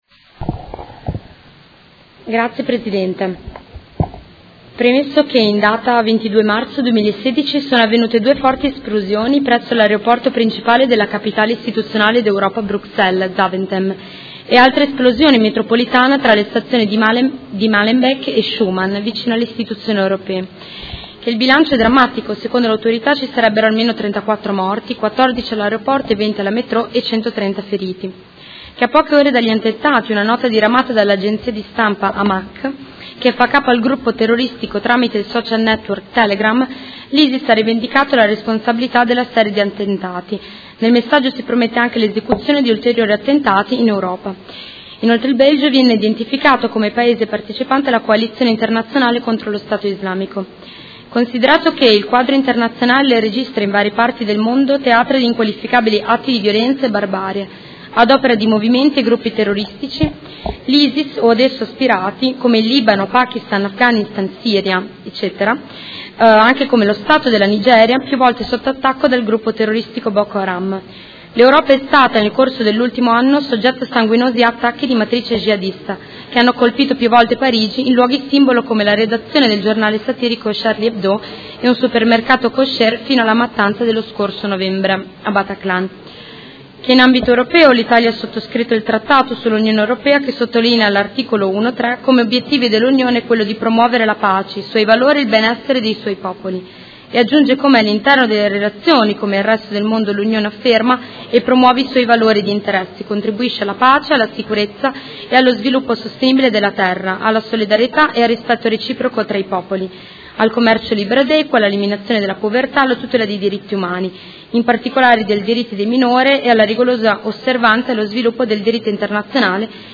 Seduta del 20/04/2016. Ordine del Giorno presentato dai Consiglieri Venturelli, Trande, Bortolamasi, Arletti, Stella, Malferrari, Morini, Pacchioni, Fasano, Forghieri e Liotti (P.D.), Consigliere Rocco (FAS) e Consigliere Cugusi (SEL) e Consiglieri Chincarini e Campana (Per Me Modena) avente per oggetto: Solidarietà al Governo belga e ai suoi cittadini e ferma condanna al terrorismo fondamentalista jihadista